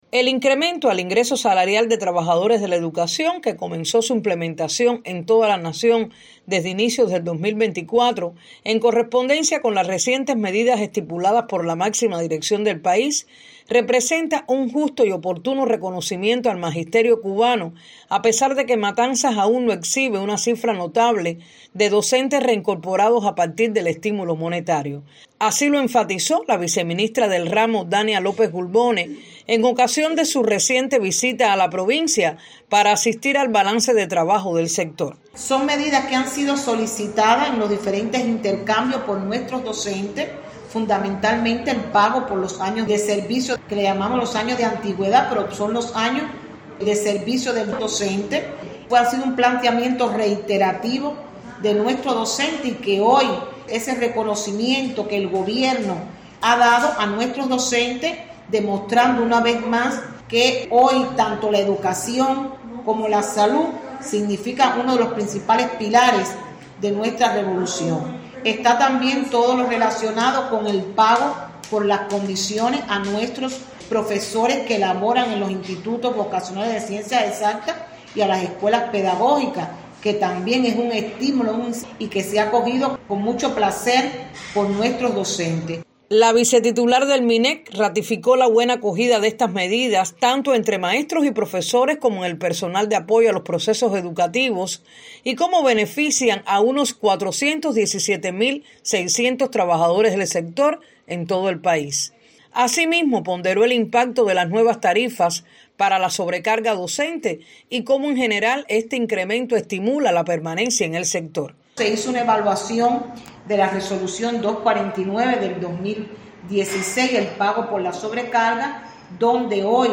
A partir de la entrada en vigor de la nueva medida, implementada con el fin de elevar los ingresos y estimular la reincorporación de profesionales al sector, han retornado a laborar en las entidades educativas sólo unos 60 profesionales de la docencia  y cuatro no docentes, “un aspecto que Matanzas debe fomentar en aras de lograr calidad  en los procesos de enseñanza-aprendizaje y en la formación integral de los estudiantes”, según precisó  la viceministra  de Educación Dania López Gulbone durante la entrevista concedida a Radio 26  en ocasión de su reciente visita a la capital yumurina.